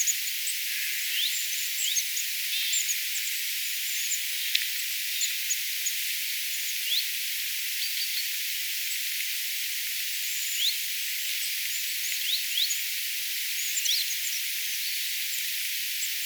hiukan nämä äänet
muistuttavat ehkäpä tiltalttia?
Kyseessä on siis talitiainen.
pikkusen_muistuttavat_nuo_talitiaisen_aanet_ehkapa_tiltaltin_aania.mp3